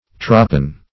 Trapan \Tra*pan"\, v. t. [imp. & p. p. Trapanned; p. pr. & vb.